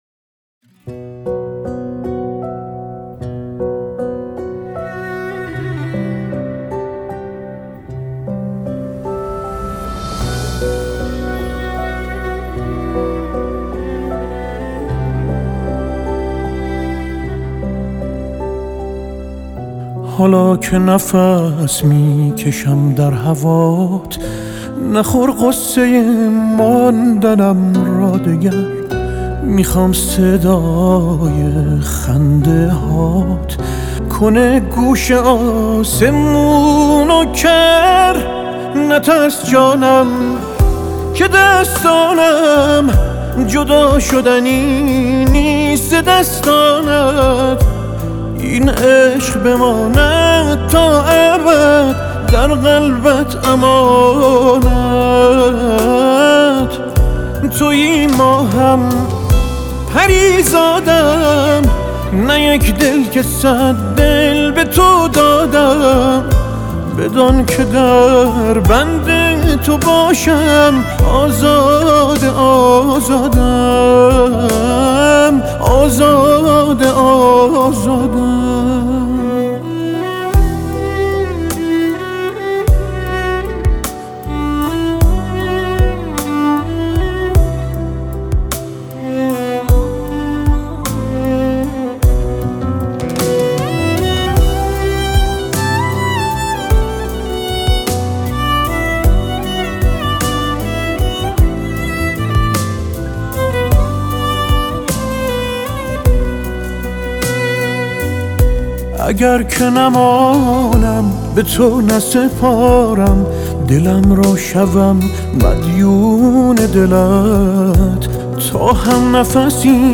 موزیک غمگین